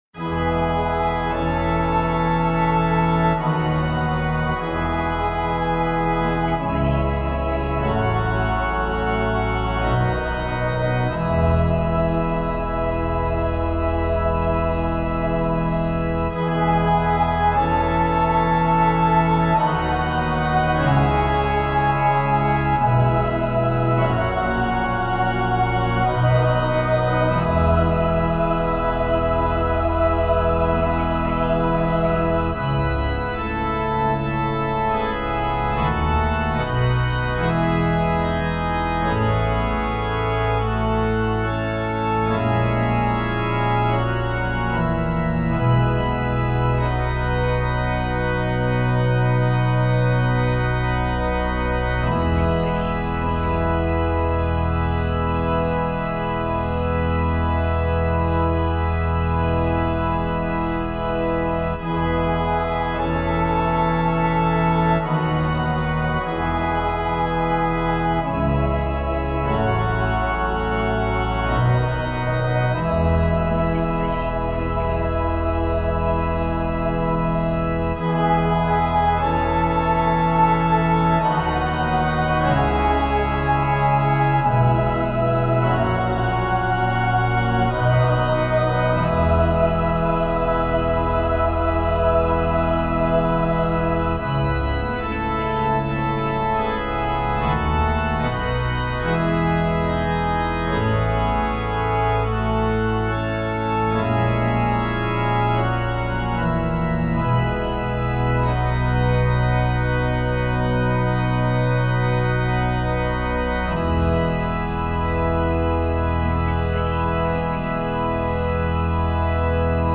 celebration – festive and joyful music for video